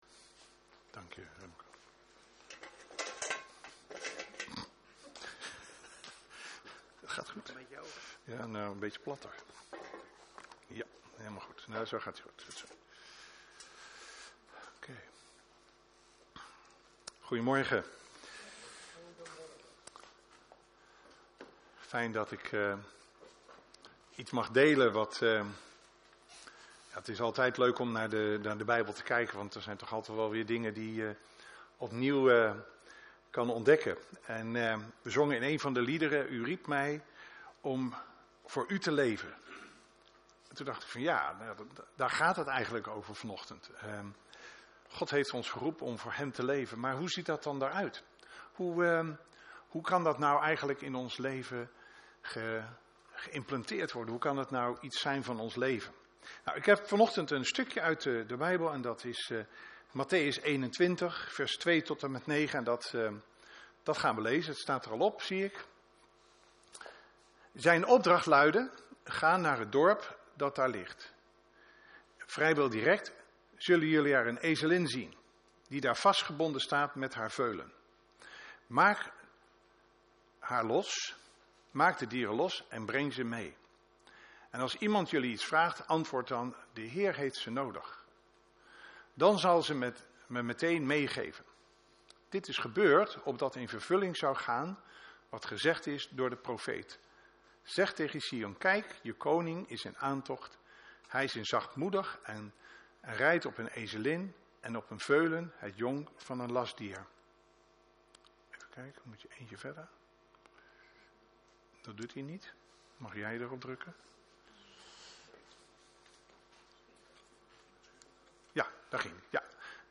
Een preek over De man met de ezelin